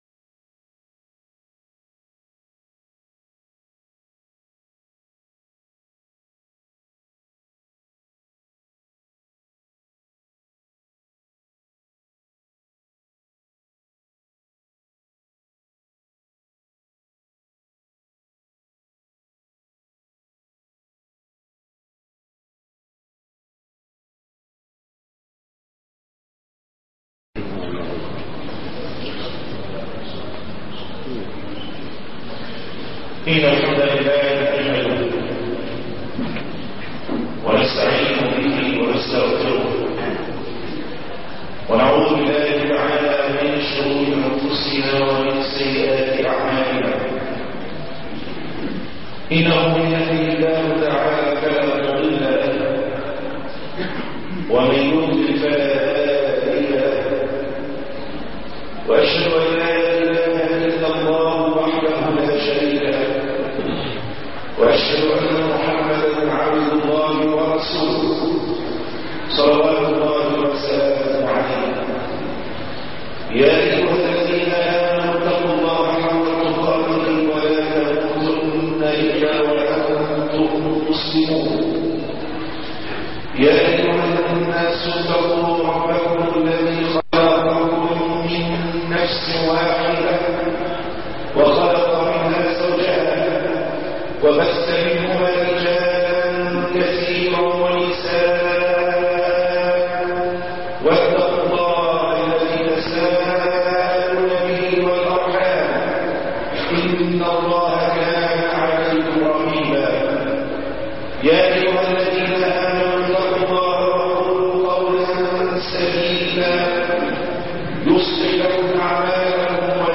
حصاد السنين- خطب الجمعه